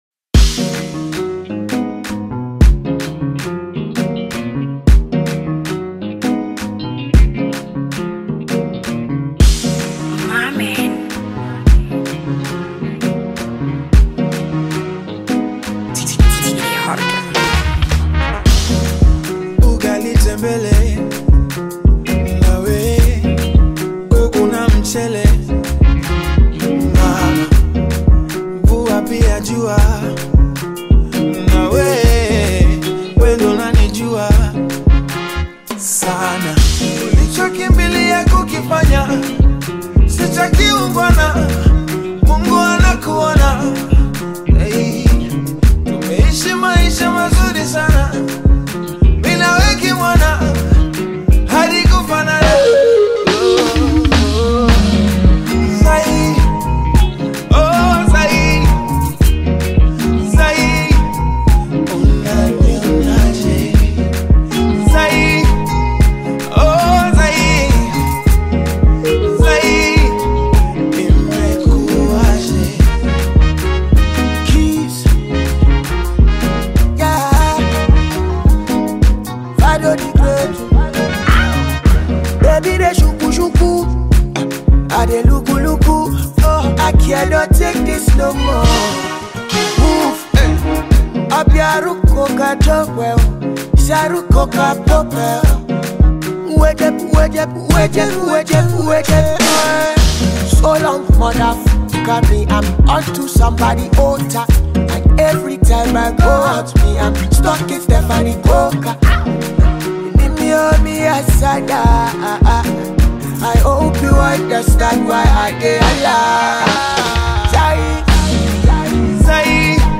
Alternative Pop